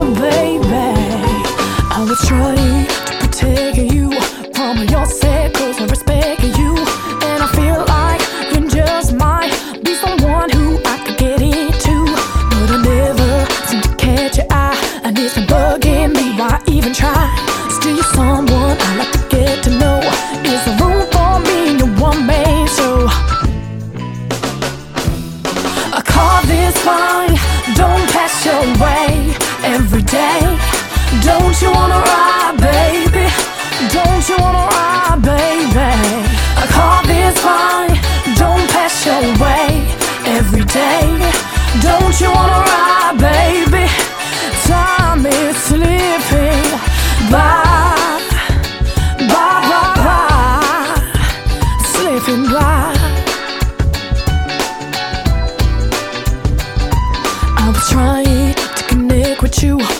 британской соул-исполнительницы